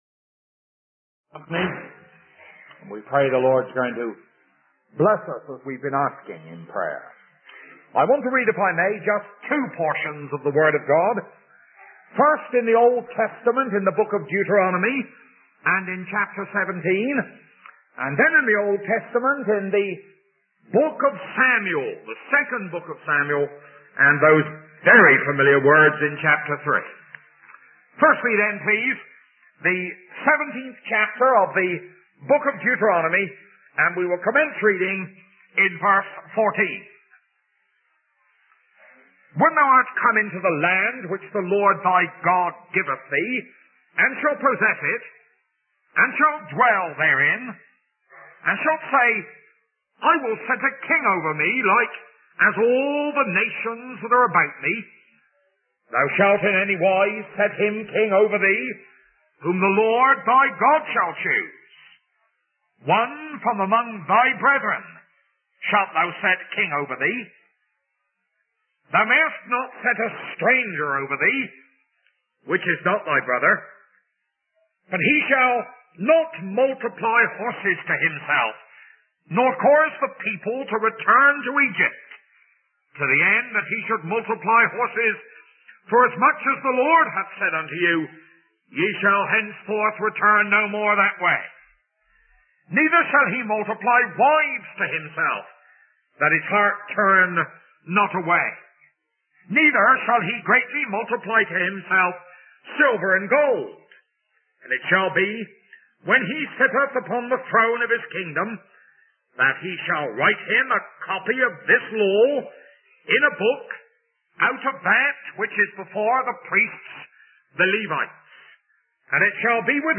In this sermon, the preacher draws parallels between the story of David and Goliath and the sacrifice of Jesus on the cross. He emphasizes the discipline and consistency of Jesus in facing poverty and temptation, refusing to use his power for self-gratification.